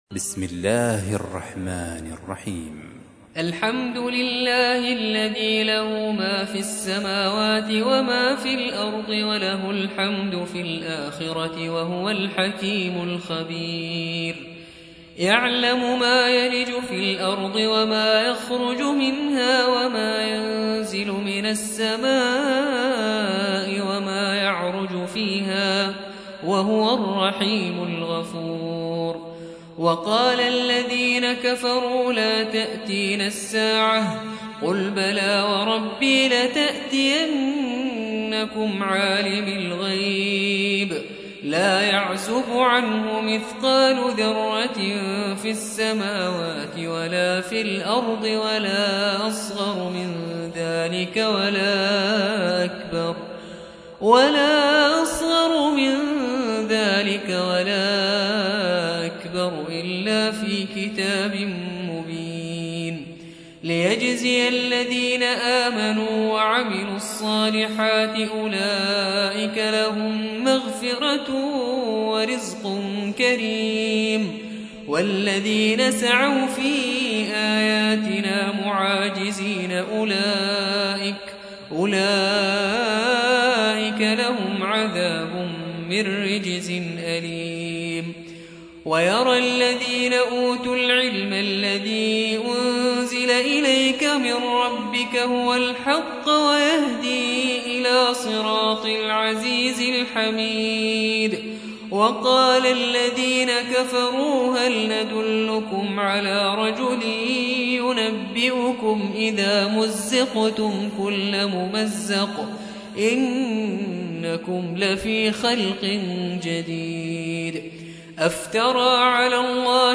تحميل : 34. سورة سبأ / القارئ نبيل الرفاعي / القرآن الكريم / موقع يا حسين